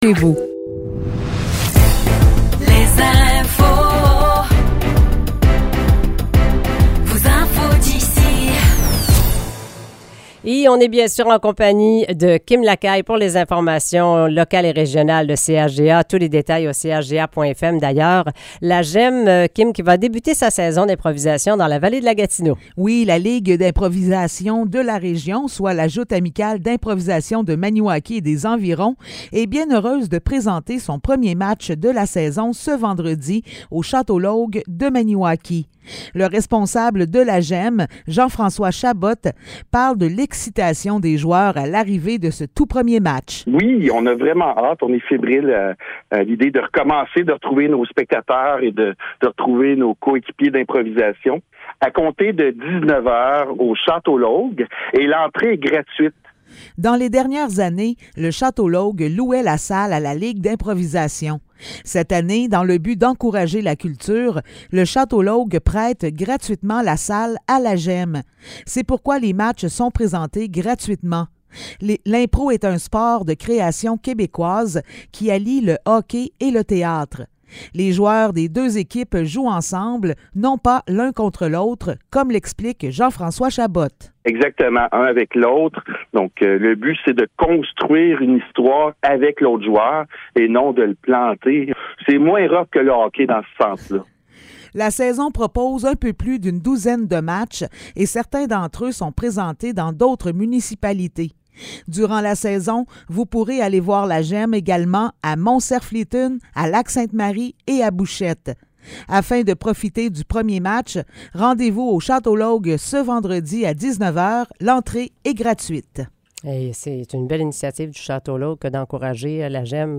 Nouvelles locales - 13 septembre 2023 - 8 h